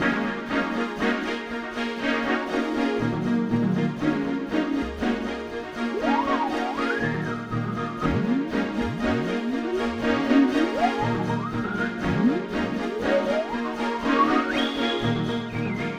Glass_120_D#.wav